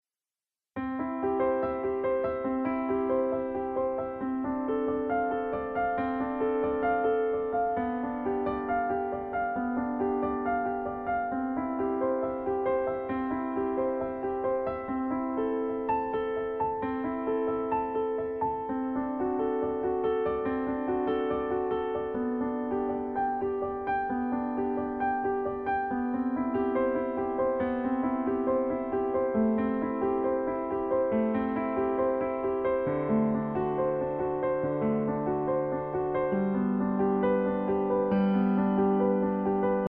・演奏の速さは♩=７０くらい（ざっくり、アンダンテの速さは６０〜１００あたりの数値で示される）
心地よく、ゆったりと身を任せることができる安心のテンポです。